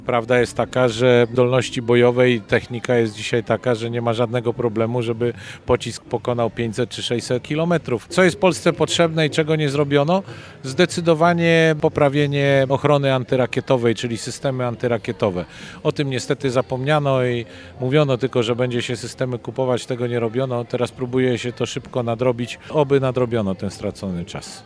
W podobnym tonie mówi polityk Nowej Lewicy Dariusz Wieczorek.